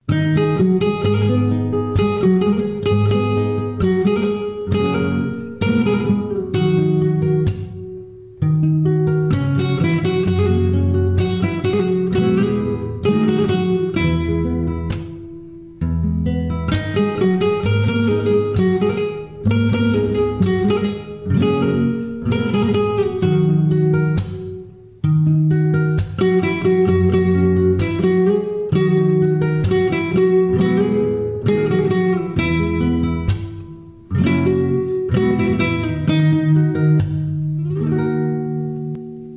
زنگ خور عاشقانه و ملایم